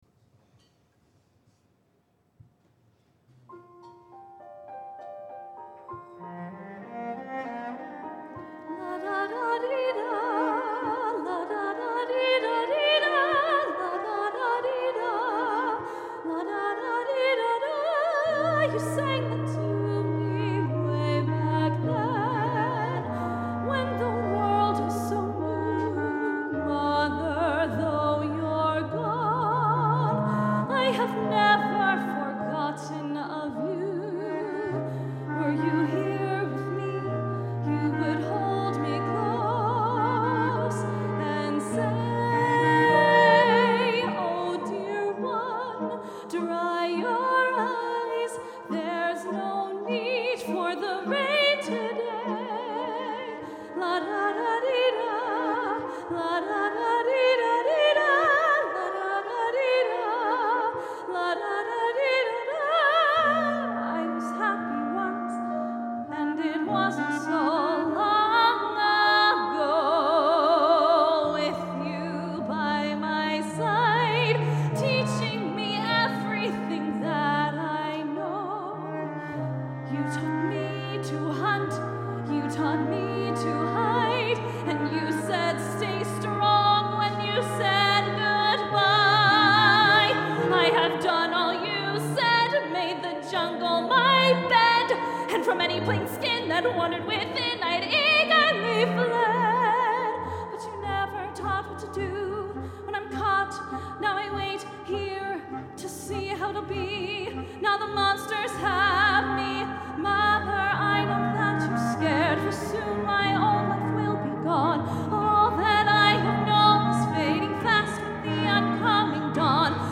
Live at Feinstein's/54 Below